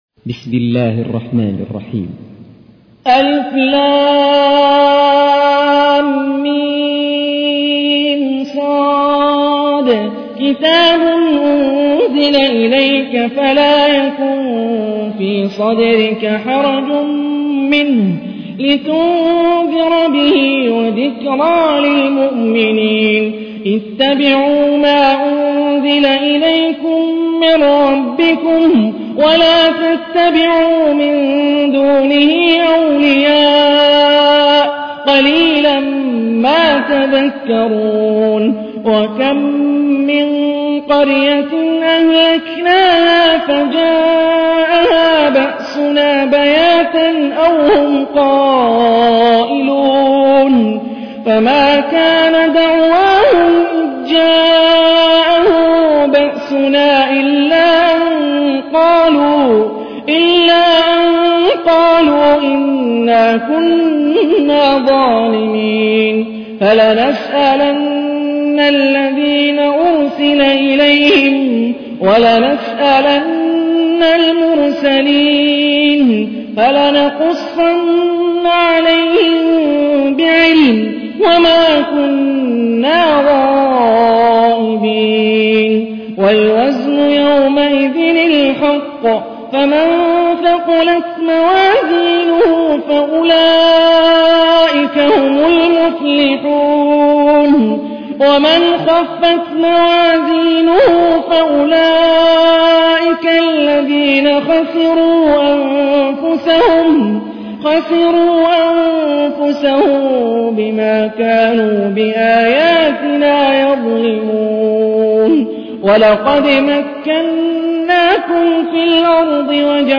تحميل : 7. سورة الأعراف / القارئ هاني الرفاعي / القرآن الكريم / موقع يا حسين